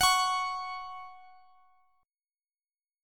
Listen to F#5 strummed